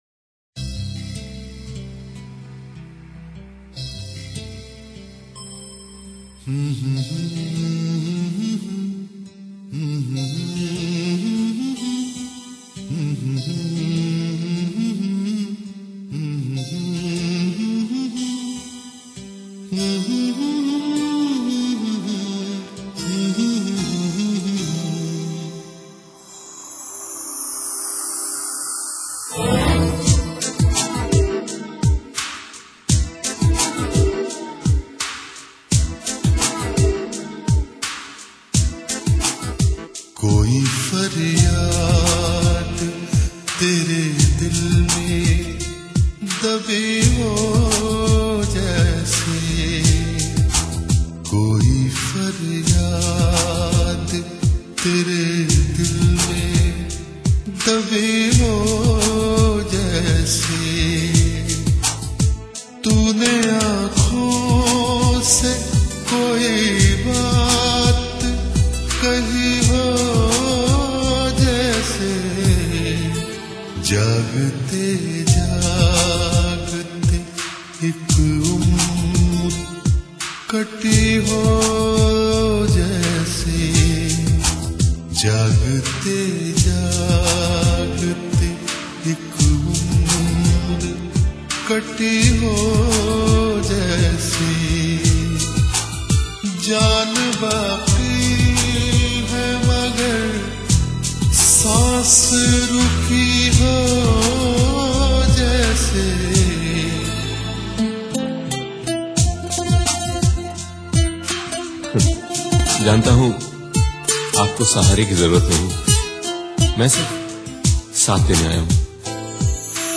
100 Best Ghazals Ever